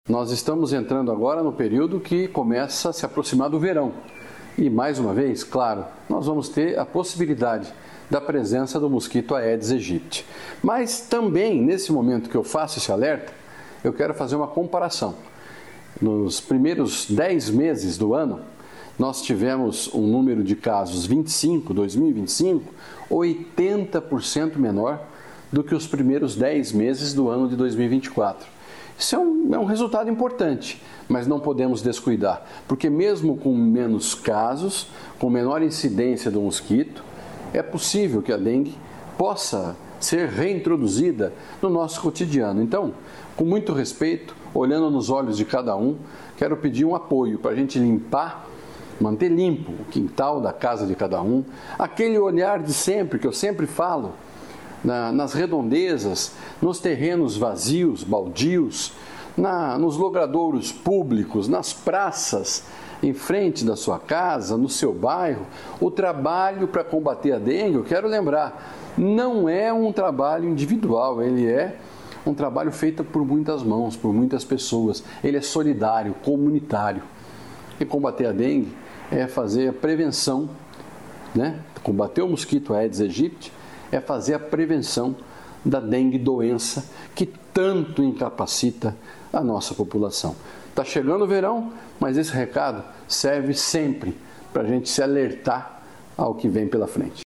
Sonora do secretário da Saúde, Beto Preto, sobre a queda de sacos e mortes por dengue